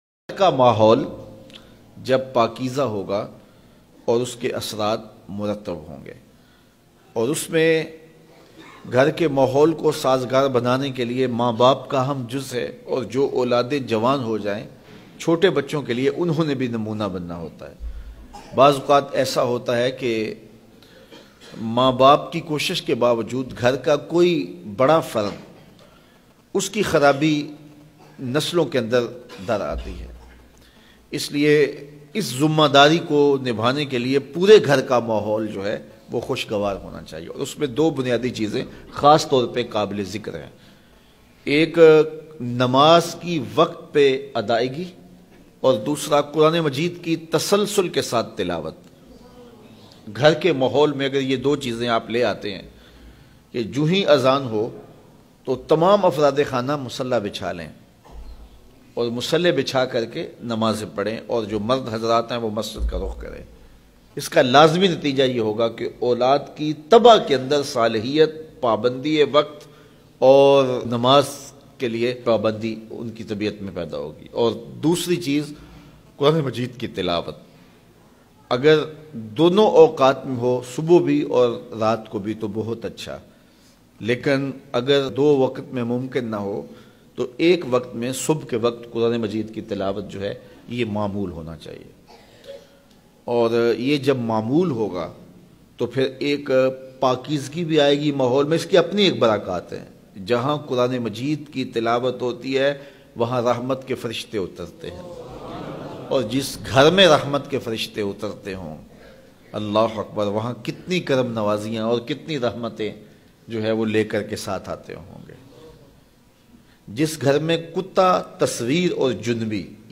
Agr Chahte Ho K Apne Gar Me Rahmat Bayan MP3